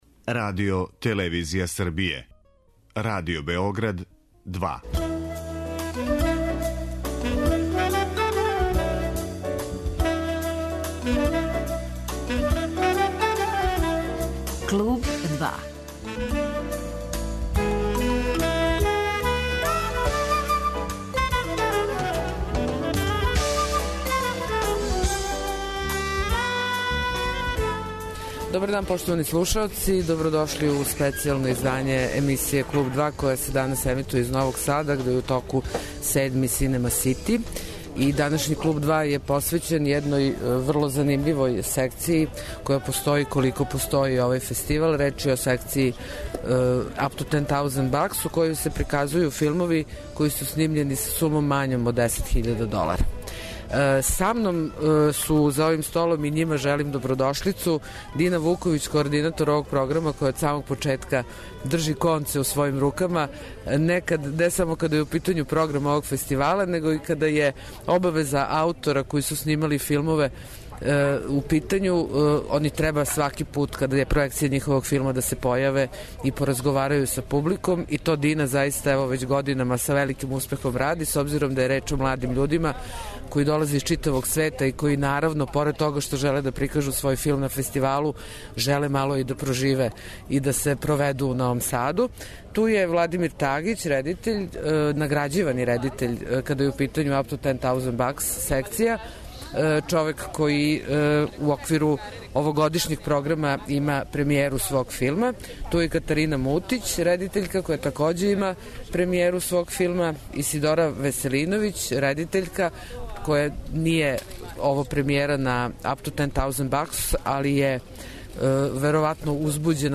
Данашње, специјално издање емисије 'Клуб 2' емитује се из Новог Сада где је у току Међународни филмски фестивал 'Cinema City'.
Гости ће нам бити аутори филмова (њих шесторо) који се такмиче у програмској целини UP TO 10.000 BUCKS. Покушаћемо да одговоримо на питање колико је буџет филма одлучујућа ставка када је реч о идеји, реализацији и коначном производу и да ли је истина да аутори no или low bidget филмова имају већу слободу уметничког израза.